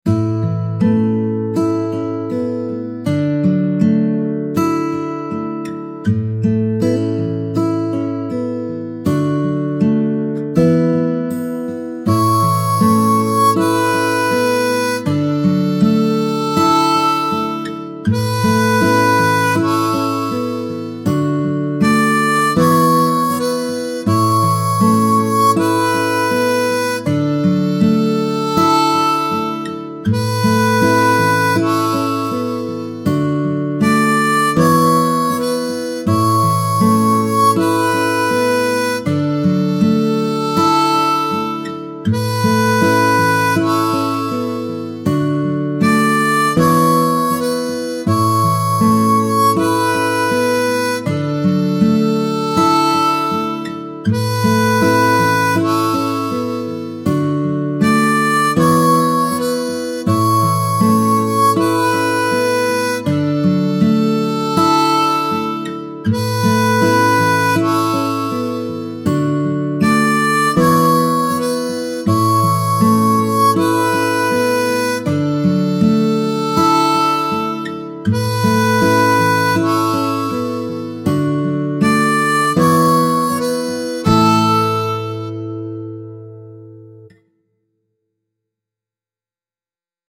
folk ballad with harmonica, acoustic guitar and a slow heartfelt tempo